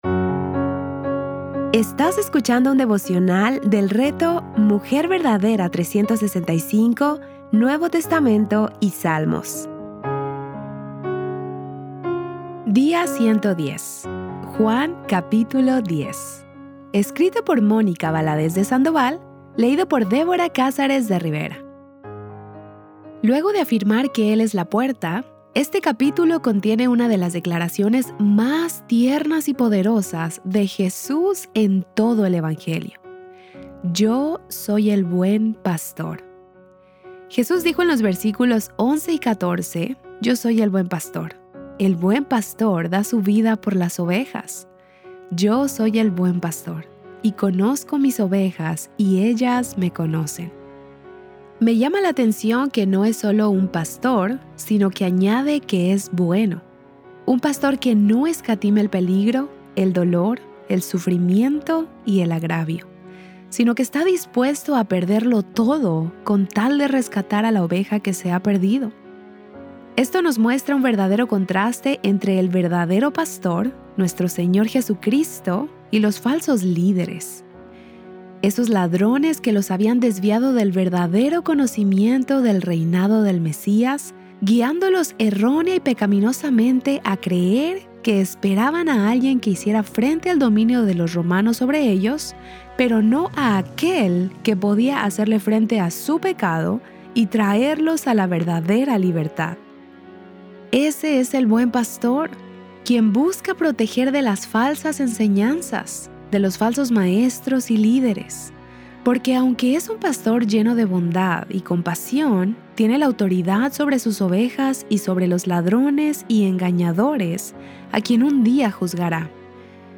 Series:  Juan y Salmos | Temas: Lectura Bíblica